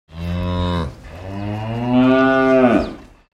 دانلود آهنگ گاو روستایی از افکت صوتی انسان و موجودات زنده
دانلود صدای گاو روستایی از ساعد نیوز با لینک مستقیم و کیفیت بالا
جلوه های صوتی